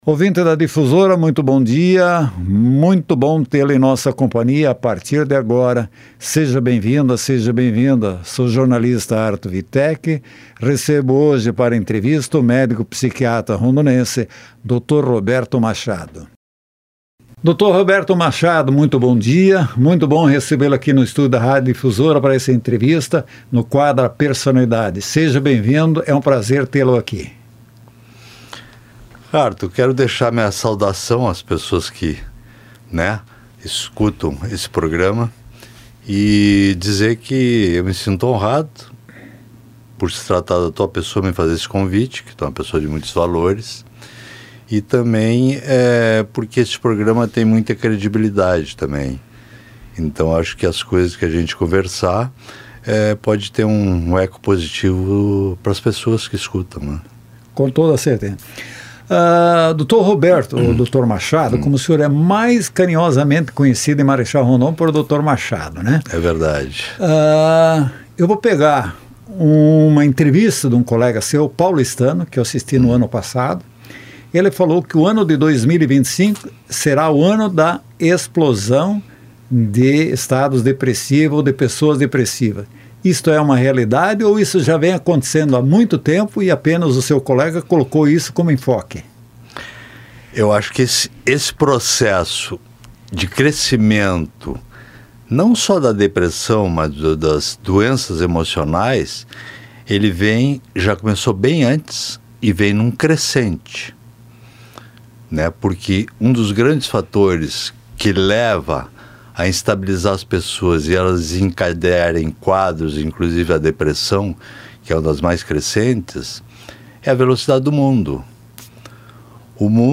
entrevistado